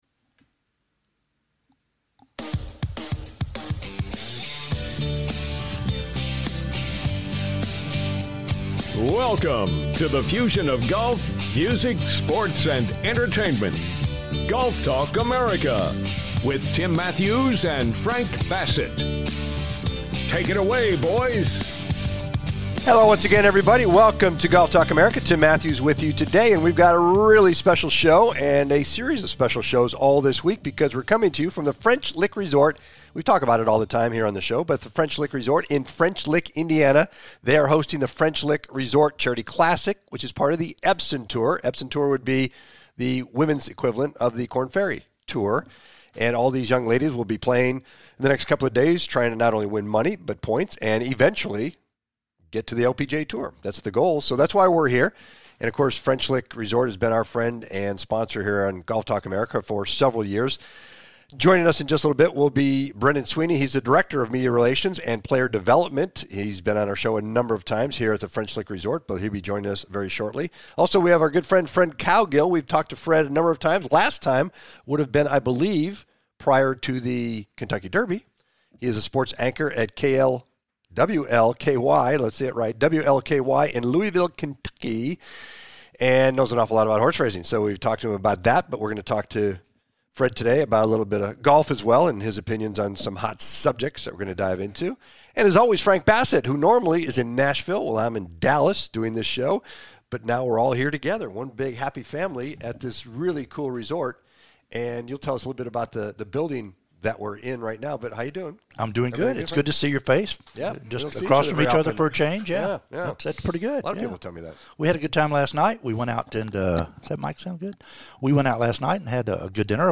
"LIVE" FROM THE EPSON TOUR'S FRENCH LICK RESORT CHARITY CLASSIC